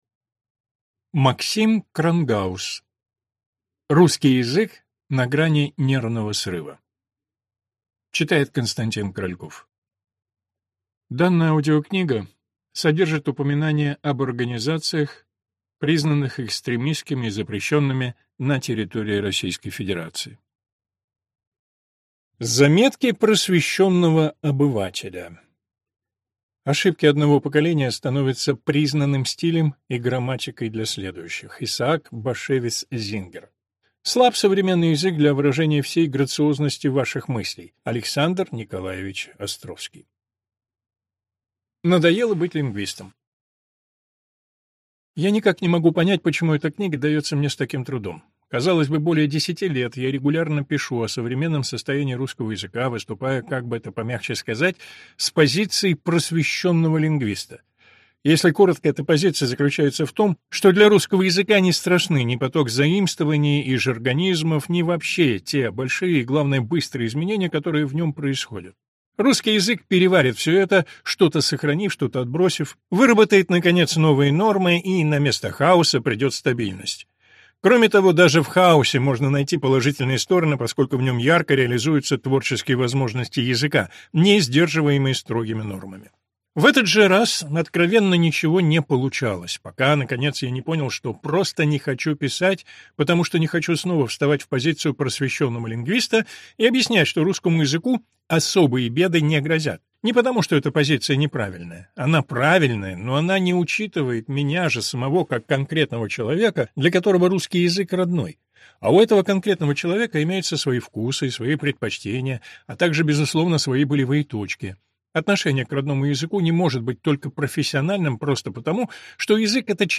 Аудиокнига Русский язык на грани нервного срыва | Библиотека аудиокниг